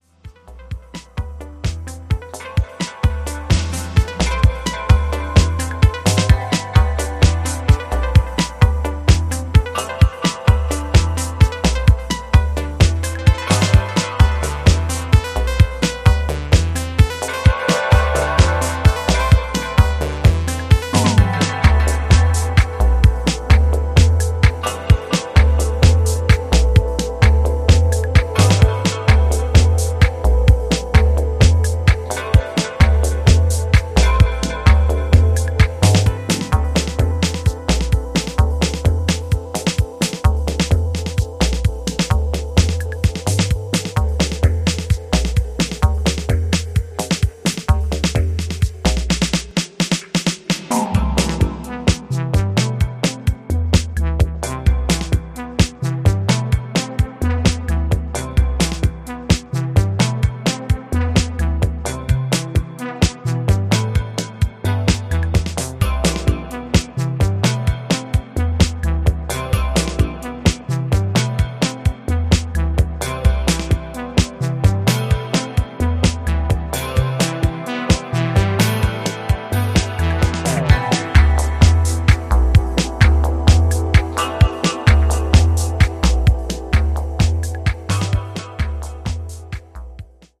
ジャンル(スタイル) DEEP HOUSE / AFRO